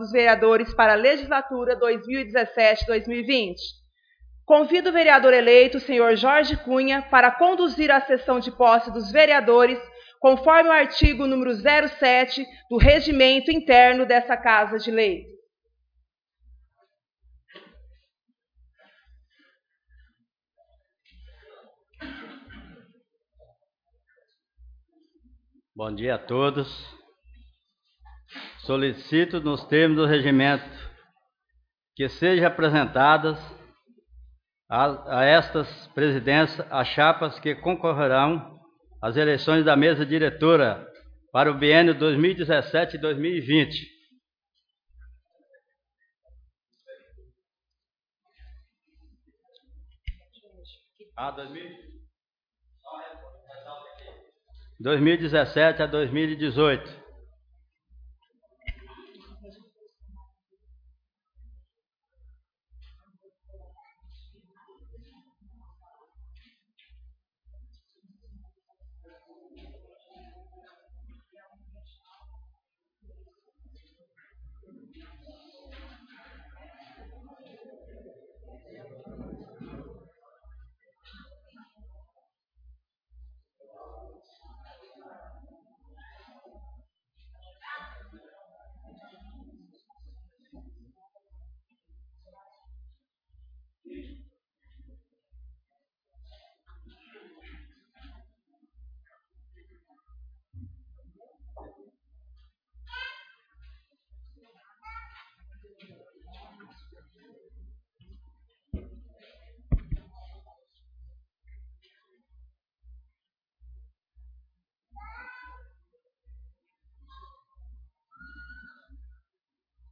Sessão Solene de Posse dos Vereadores 01/01/2017